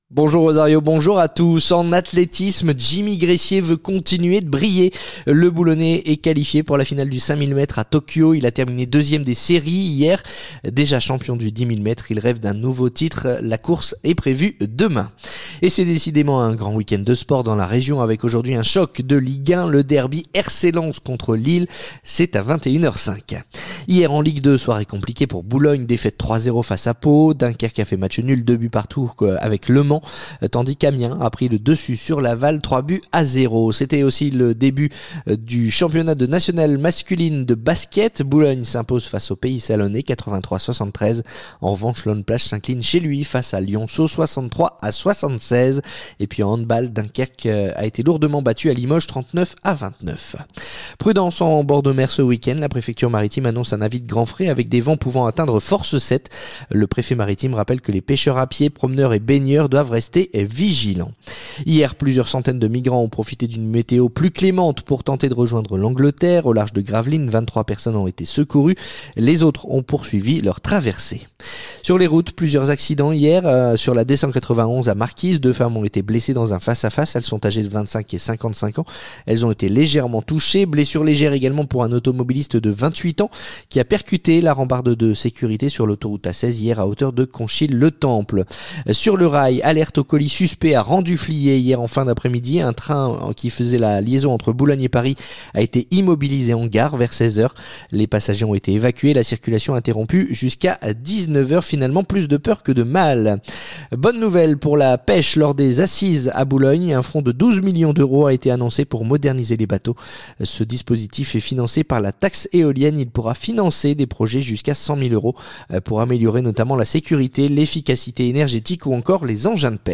Le journal du samedi 20 septembre